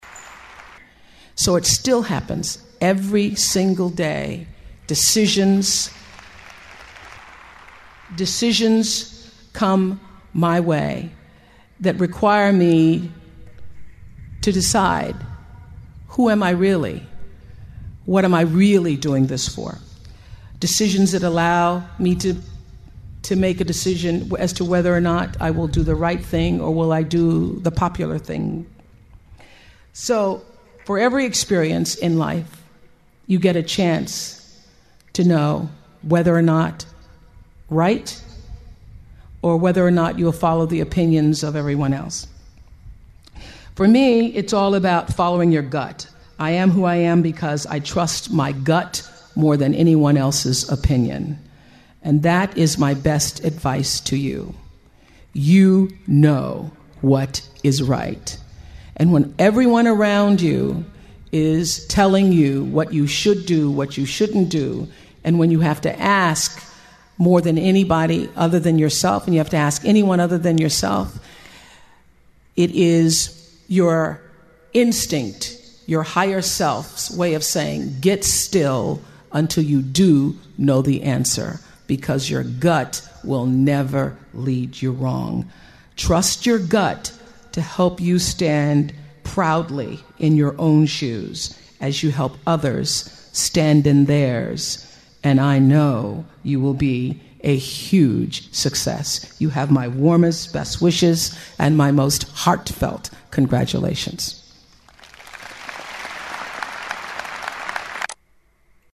名校励志英语演讲 86:追随自己的心声,你们一定会成功 听力文件下载—在线英语听力室